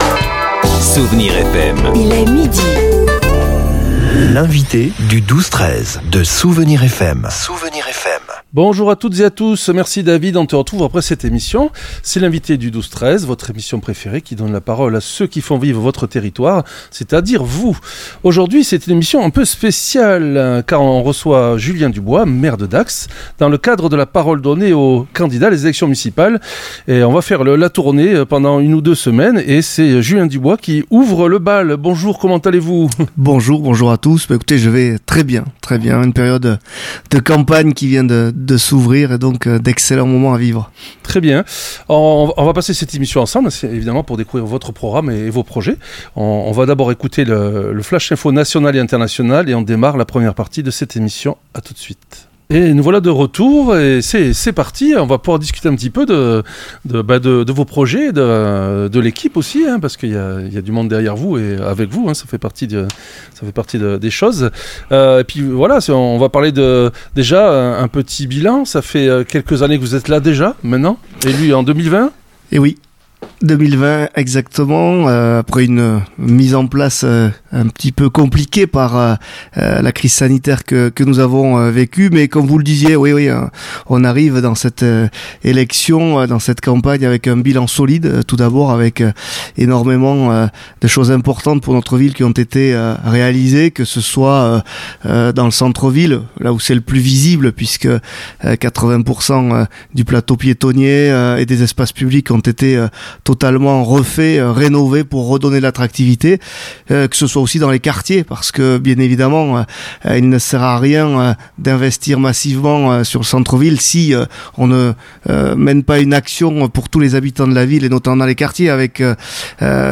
L’invité(e) du 12-13 recevait aujourd’hui Julien Dubois, maire sortant de Dax, venu présenter les grandes lignes de son programme et le bilan de son mandat.